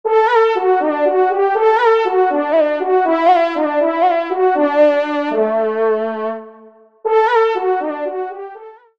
FANFARE